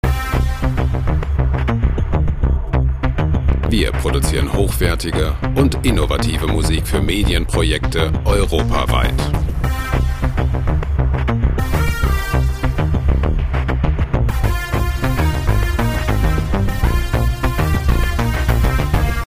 • Synthie Pop
düsterer Synthie Pop mit zackigem Melodie-Riff 19,00 EUR